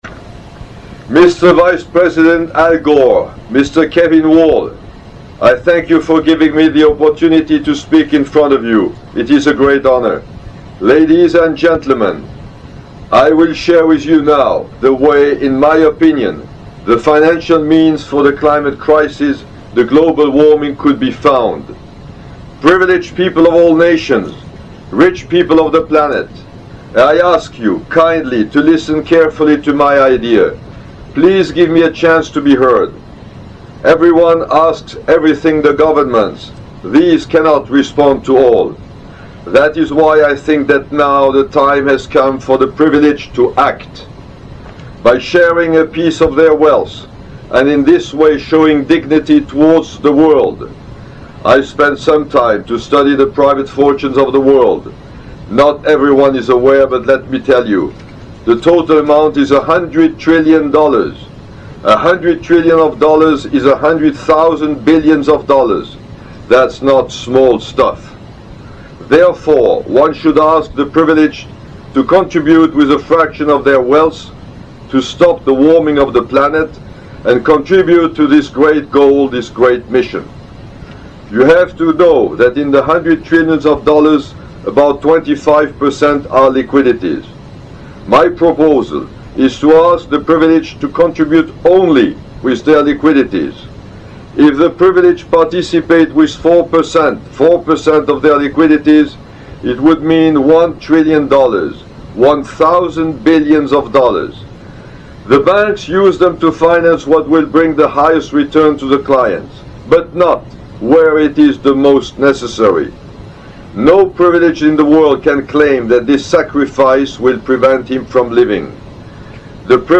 This speech was recorded for the Event of LIVE EARTH on 7/7/07 in London, Wembley Stadium. This Event was a major step in the fight against the climate crisis, the warming of the planet.
speech_for_sos_live_earth.mp3